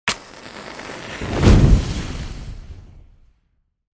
دانلود آهنگ آتش 8 از افکت صوتی طبیعت و محیط
دانلود صدای آتش 8 از ساعد نیوز با لینک مستقیم و کیفیت بالا
جلوه های صوتی